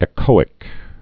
(ĕ-kōĭk)